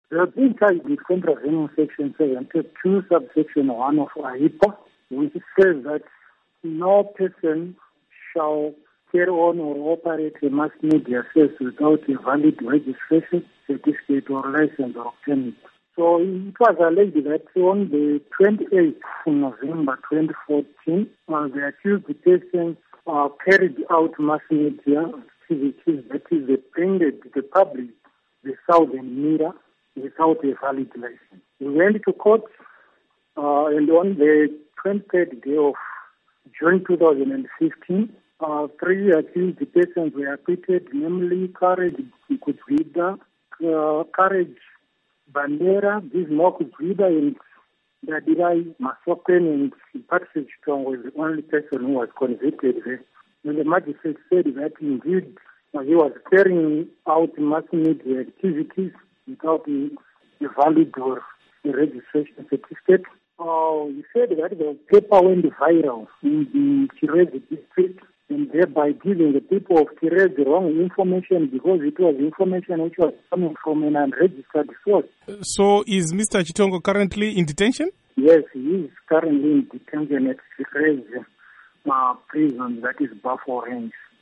Report on Arrested Journalist